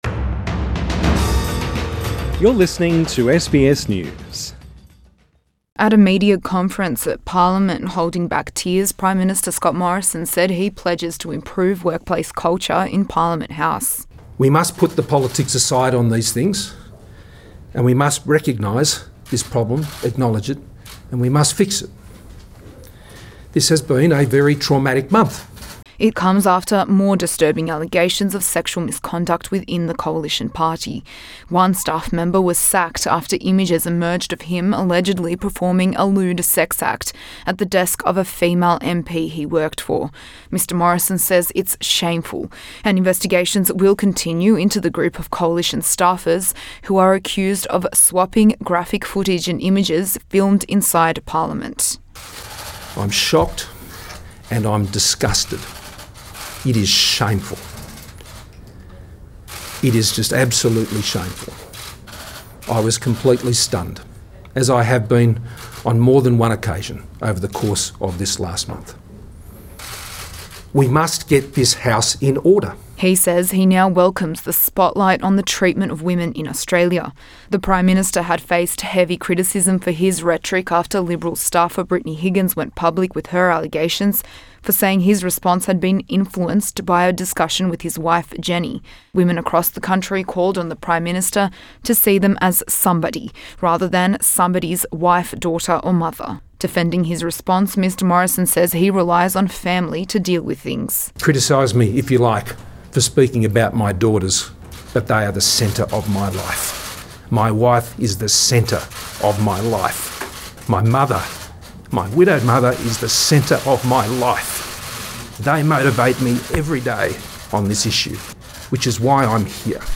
An emotional Scott Morrison during a press conference at Parliament Source: AAP